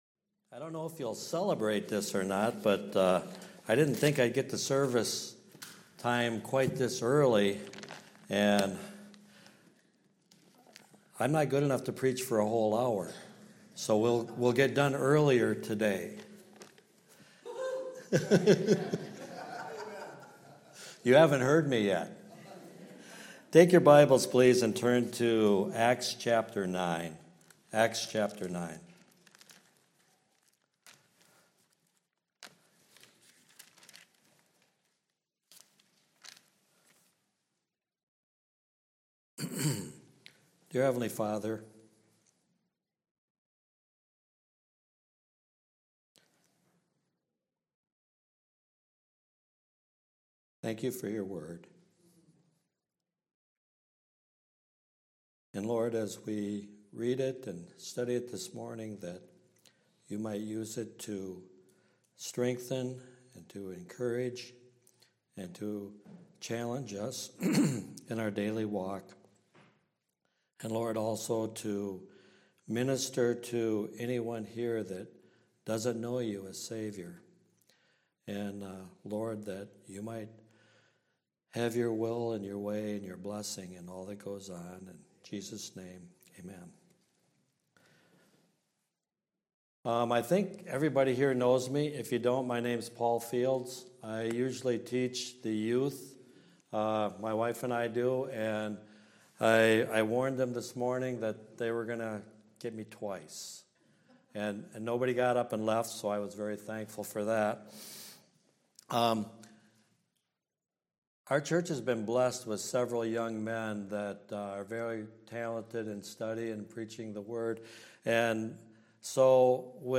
Passage: Acts 9:1-16 Service Type: Sunday Morning Service Related « The Sanctity of Life and the Certainty of Death No message uploaded this week due to technical difficulties.